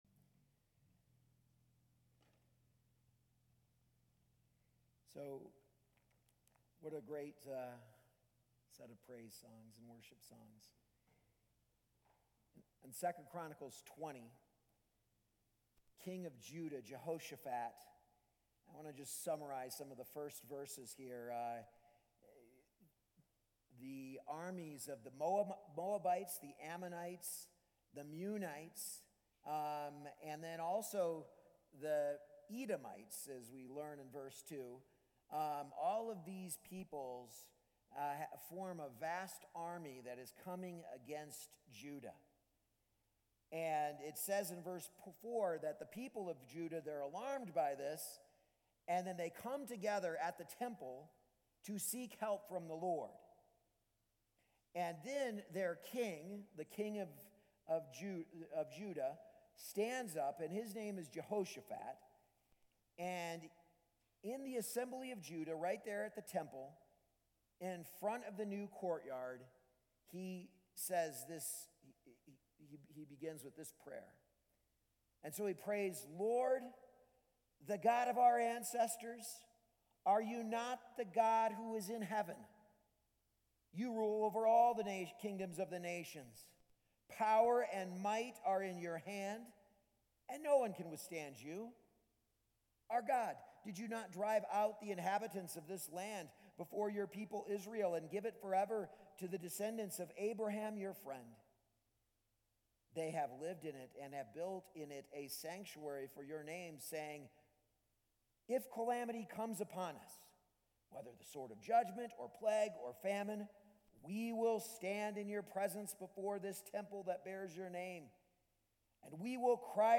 A message from the series "Daring to Draw Near."